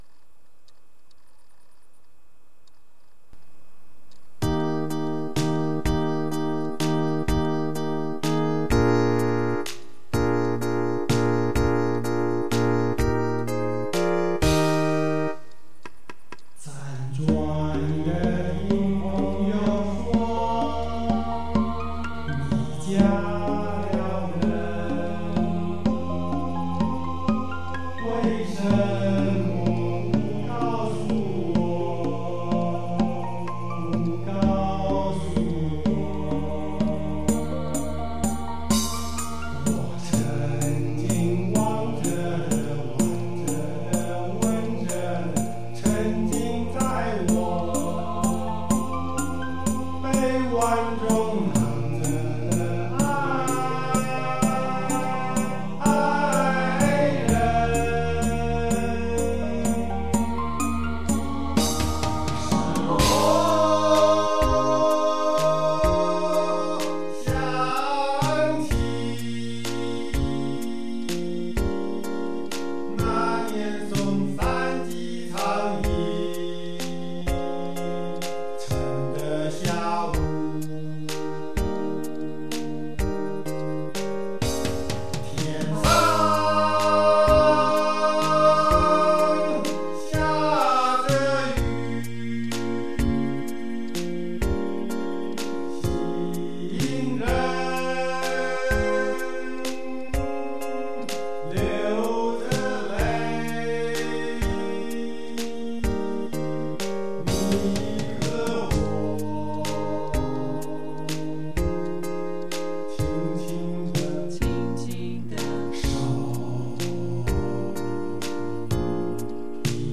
演唱曲 Singing- 請先關掉頁首收音機，再按此圖示- Please tern off the radio on the top of this page, then click here.